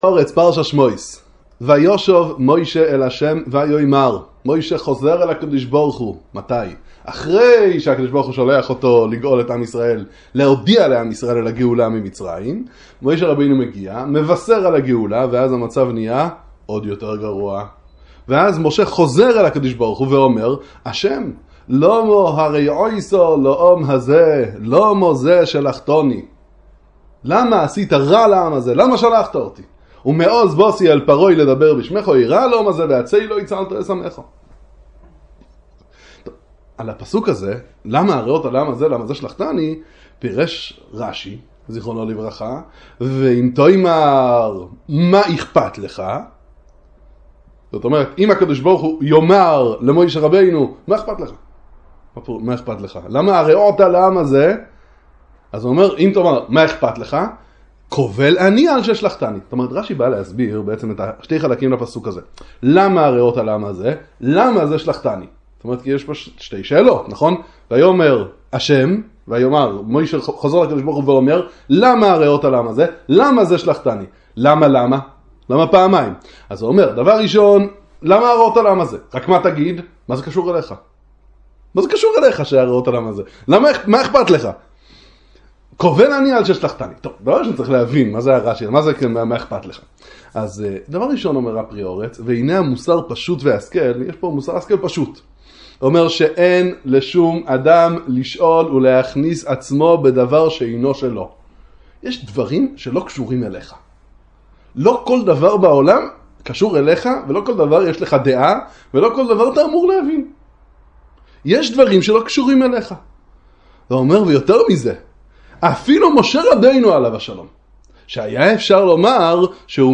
שיעור בספר פרי הארץ על פרשת השבוע